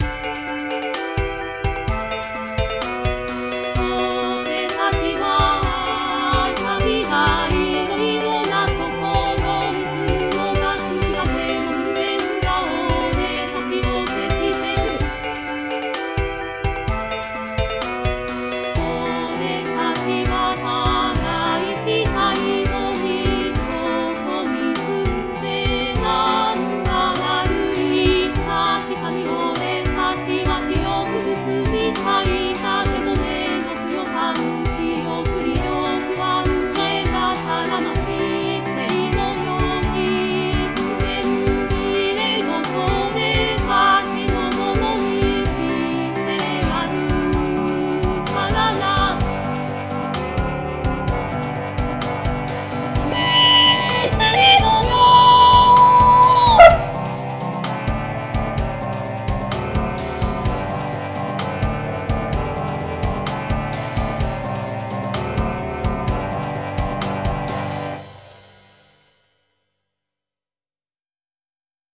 最後音量注意